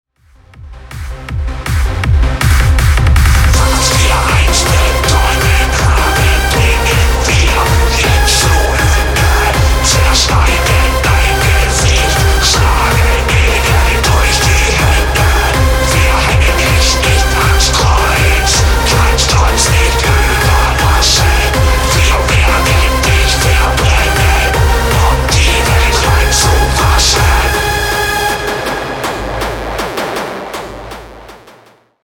Darkwave, EBM, Industrial